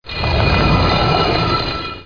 slabslide.mp3